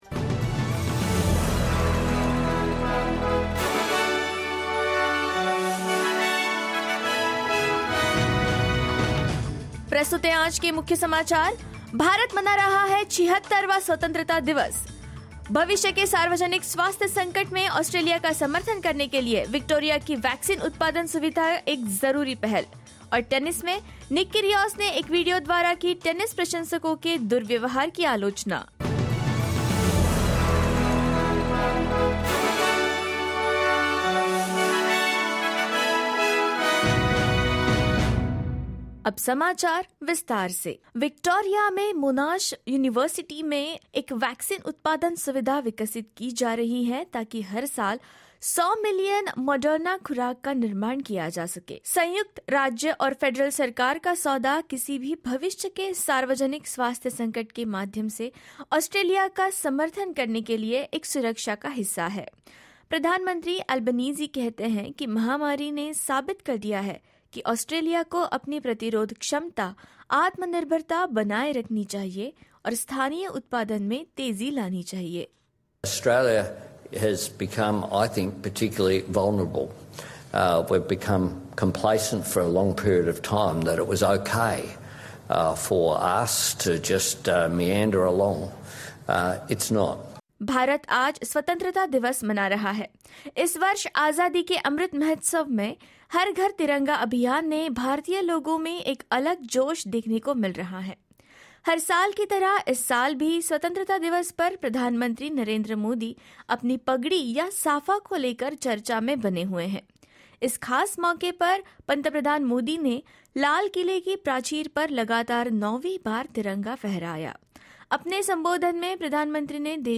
SBS Hindi News 15 August 2022: India celebrates 76th Independence day with fervour